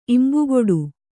♪ imbugoḍu